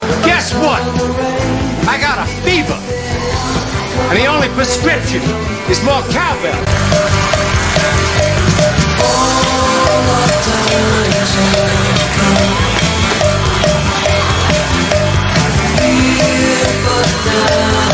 more-cowbell.mp3